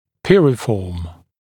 [ˈpɪrɪfɔːm][ˈпирифо:м]грушевидный, грушеобразный